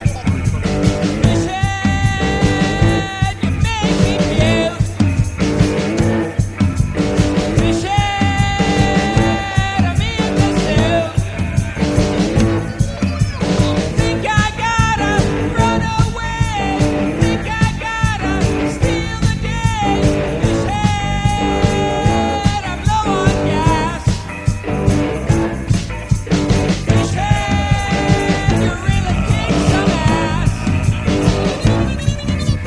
the drums and fish sounds
boogie bass and backing guitar